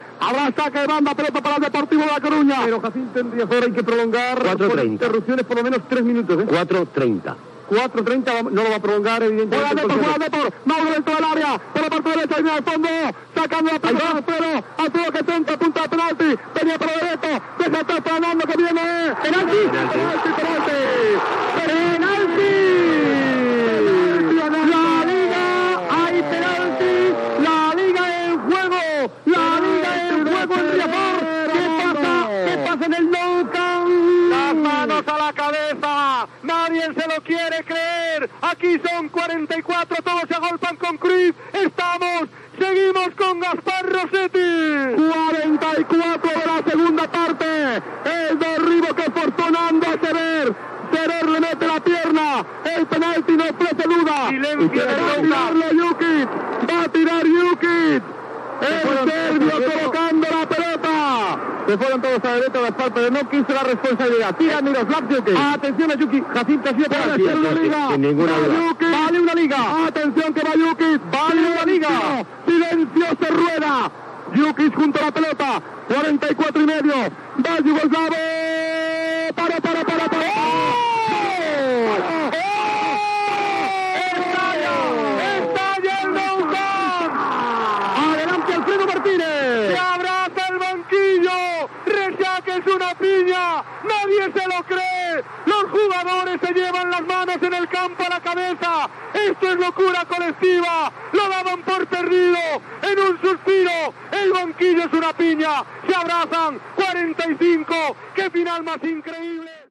Narració dels minuts finals dels partits de l'última jornada de la lliga masculina de futbol des del Nou Camp i Riazor. A Riazor el porter González, del València, atura un penalti al minut 90 al serbi Djukic del Deportivo de la Coruña.
Esportiu